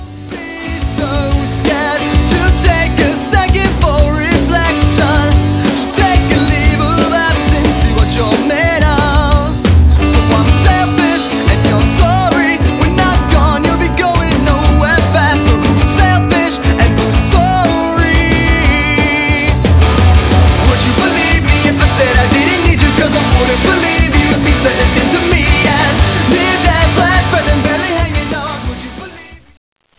Genre: Punk/Pop